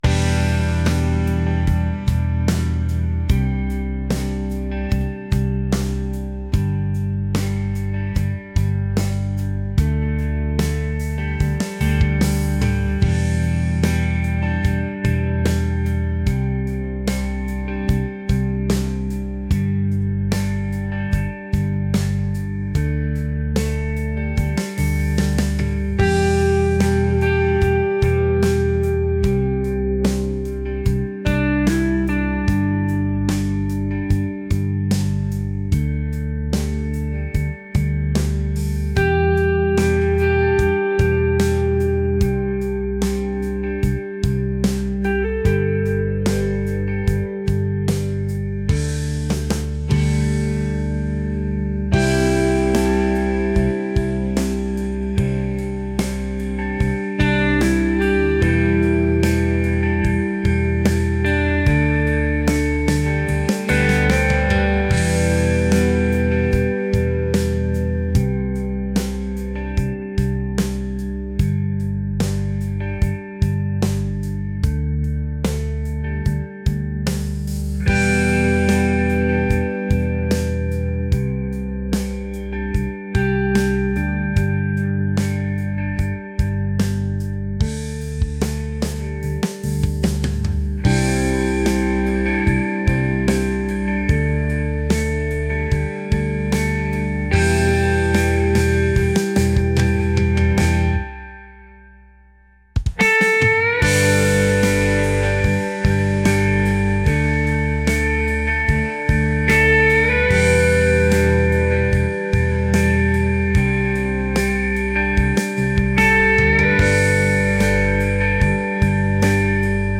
alternative | rock